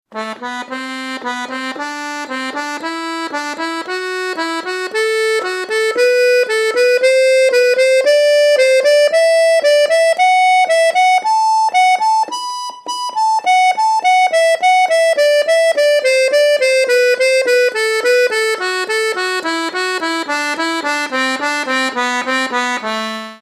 Accordeon diatonique et Musiques Traditionnelles
GAMMES SYNTHETIQUES = gammes incomplétes, les notes en sens inverse du soufflet ne sont pas jouées.
4 - Gamme synthétique de Sol normal par 3 en tiré par 3
gammesynthetiquesoltpar3normal.mp3